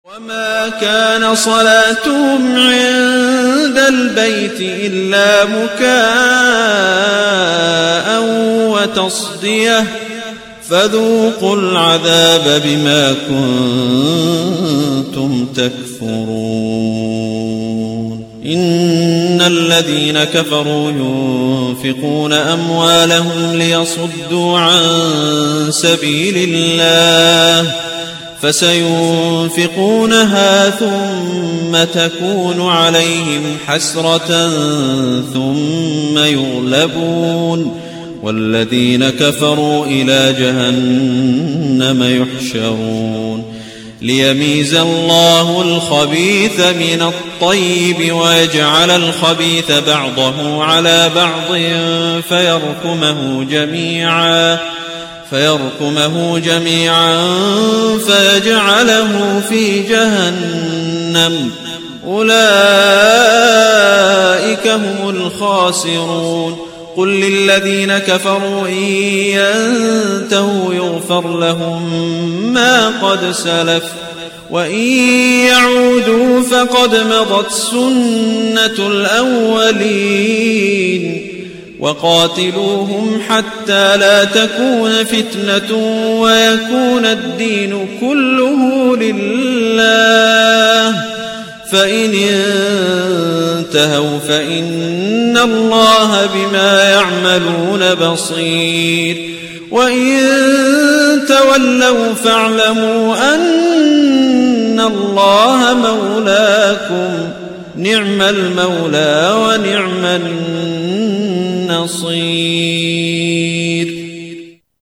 من هو هذا القارئ :